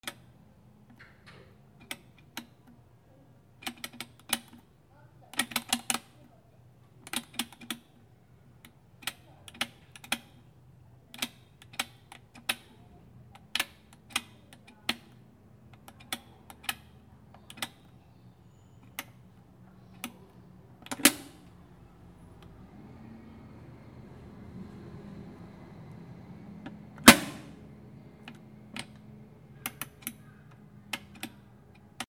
ロッカーのダイヤルを回す音
/ M｜他分類 / L10 ｜電化製品・機械
雑音気になる H4n KM